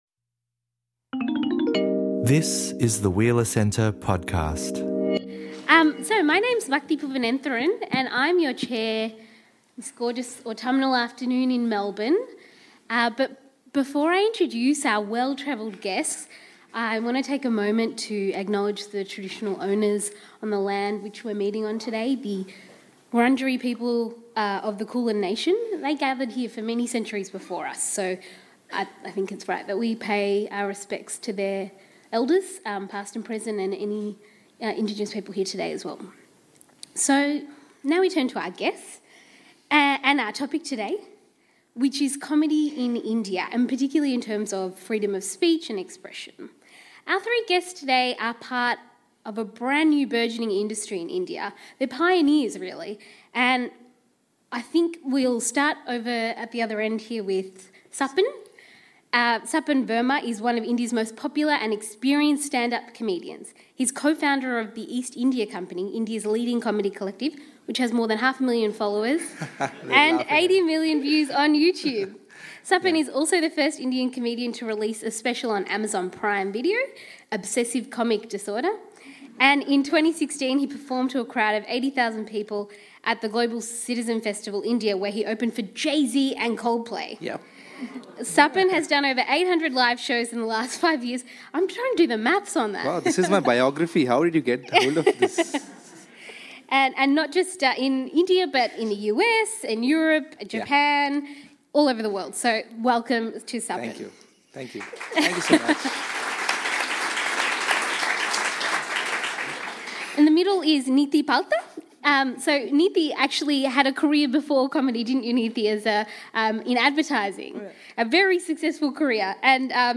In this panel discussion